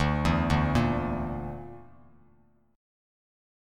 Dsus2#5 chord